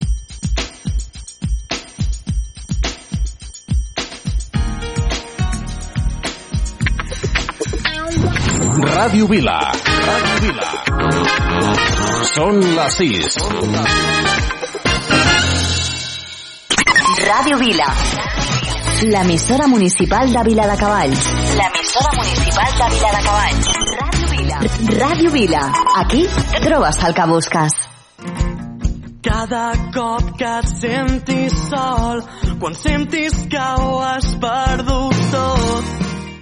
Indicatiu de l'emissora, hora i tema musical